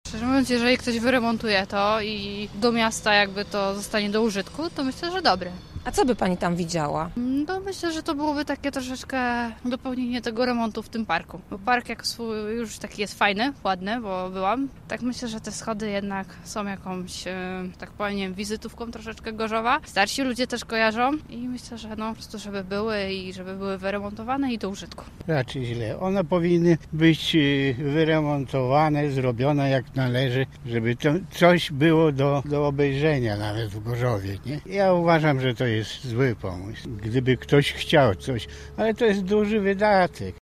Sonda RG: Głosy o sprzedaży schodów niemal po połowie
O to samo pytaliśmy też dziś w mieście.